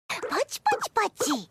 Play, download and share Puck Clap original sound button!!!!
puck-clap.mp3